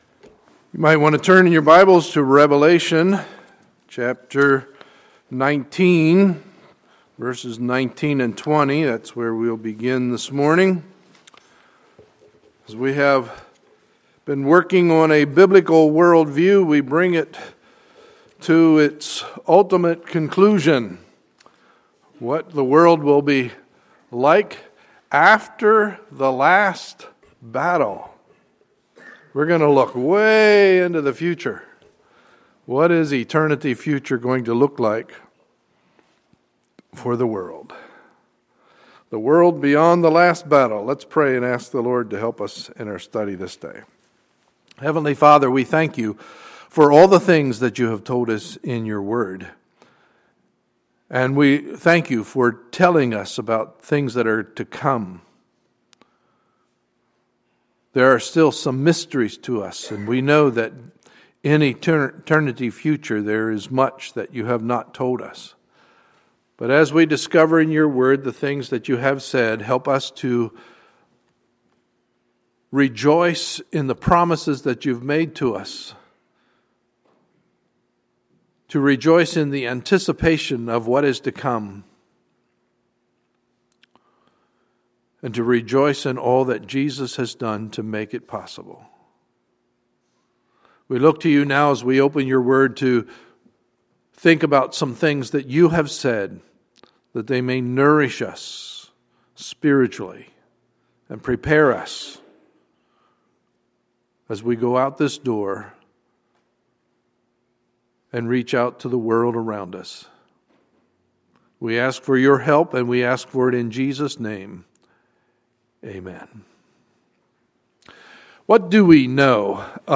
Sunday, November 16, 2014 – Sunday Morning Service